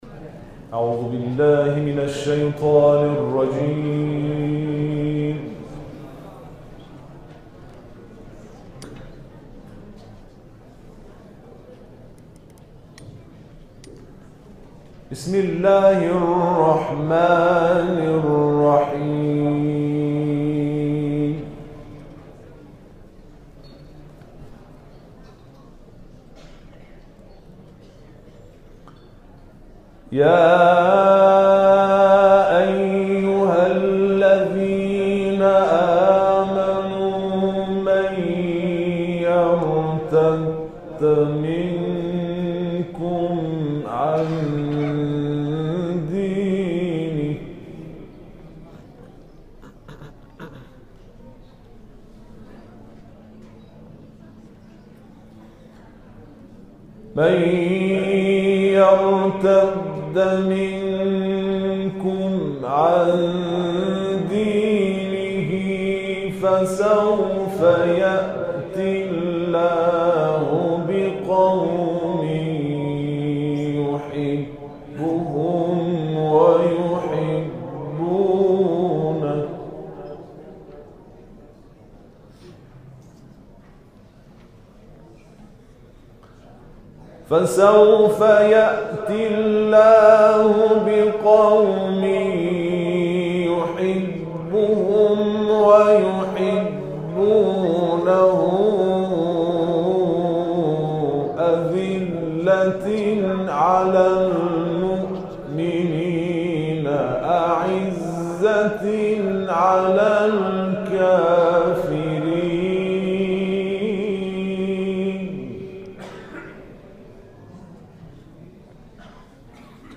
قرائت مجلسی